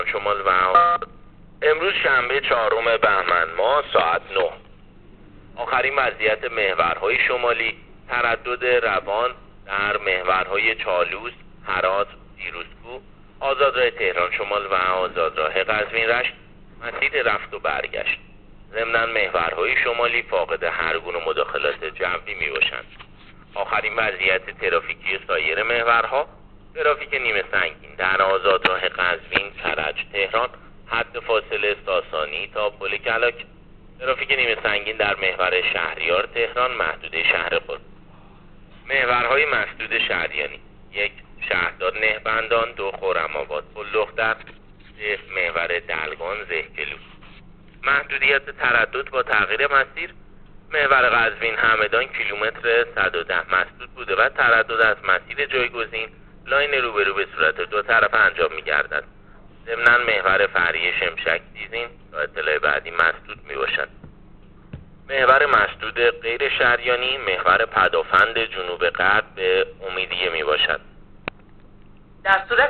گزارش رادیو اینترنتی از آخرین وضعیت ترافیکی جاده‌ها تا ساعت ۹ چهارم بهمن